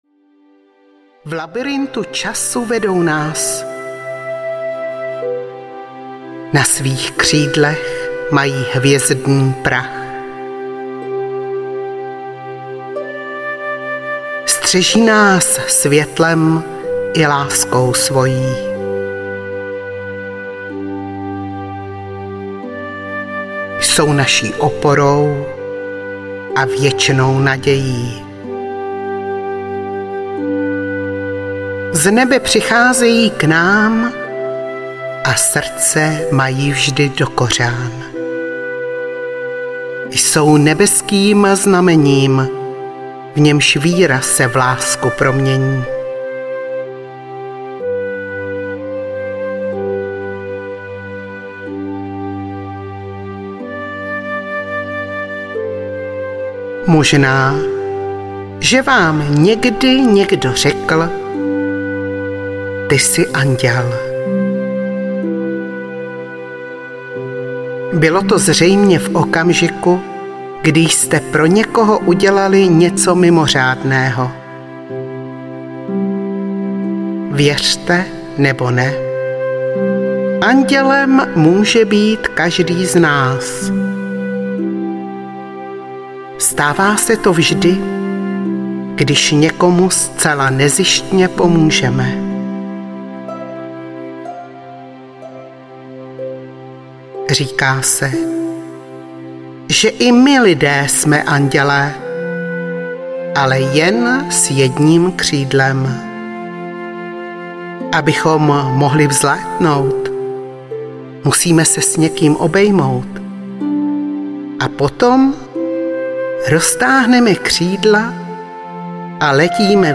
Rozjímání s anděly audiokniha
Obsahuje maximální počet alfa rytmů, které produkuje lidský mozek.